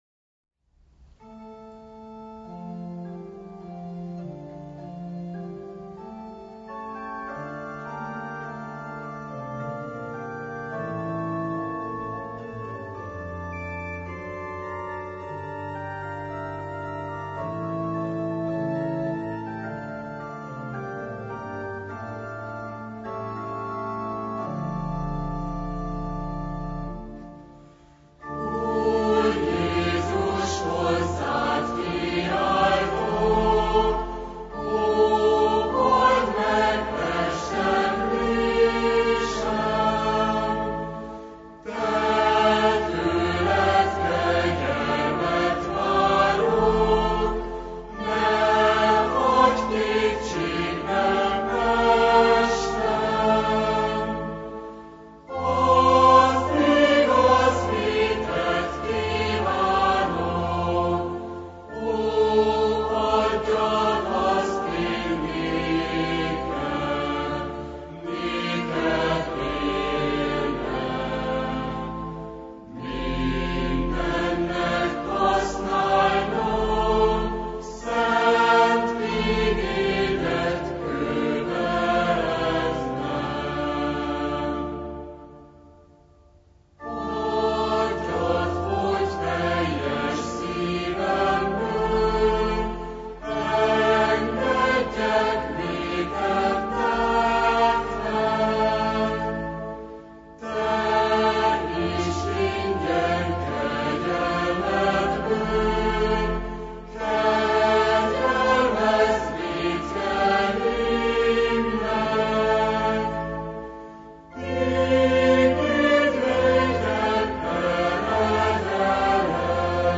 református lelkész.